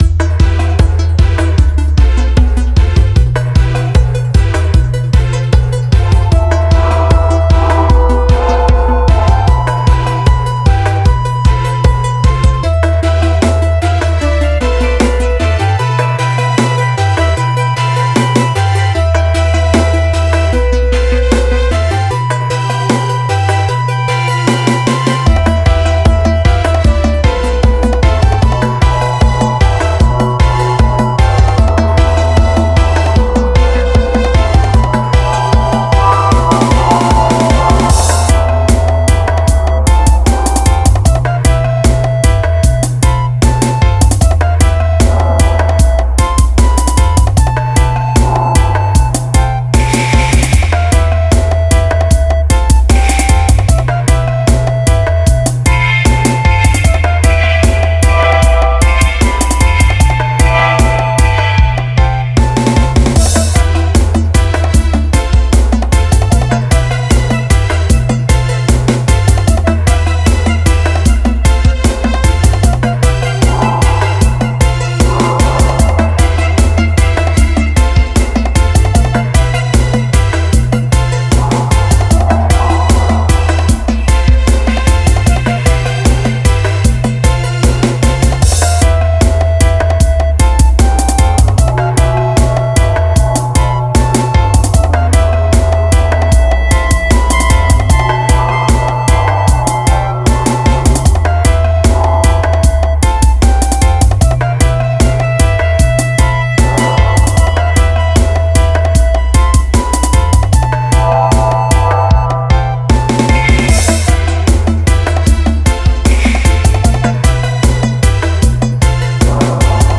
mixing their drum, bass, melody style.